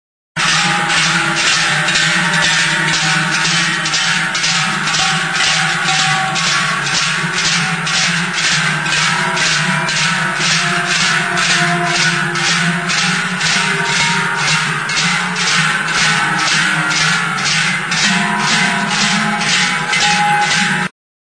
DUNBA; TRUCO; TRUCA | Soinuenea Herri Musikaren Txokoa
ITUREN ETA ZUBIETAKO JOALDUNAK. Zubieta, 1998.
Sekzio sasi borobila, tripa zabala eta aho estua duen ezkila handia da.
Larruzko zintarekin lotutako ezpelezko mihia du. 13 litrokoa da.